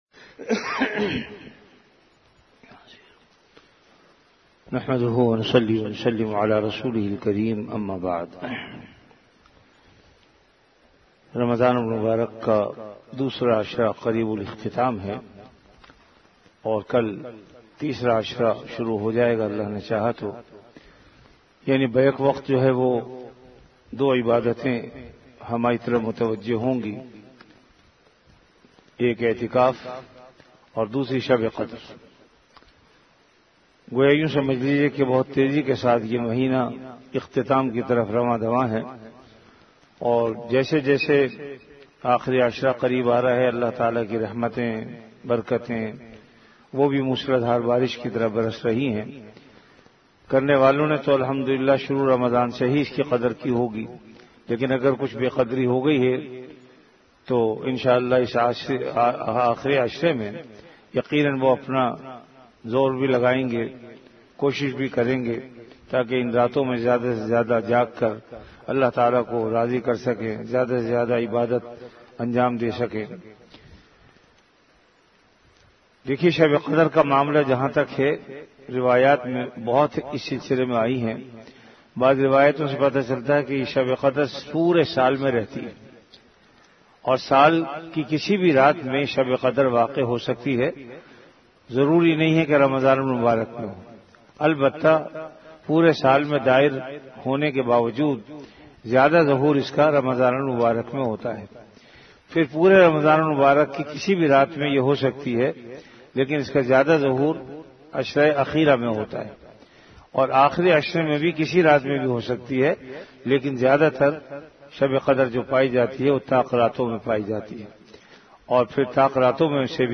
Delivered at Jamia Masjid Bait-ul-Mukkaram, Karachi.
Ramadan - Dars-e-Hadees · Jamia Masjid Bait-ul-Mukkaram, Karachi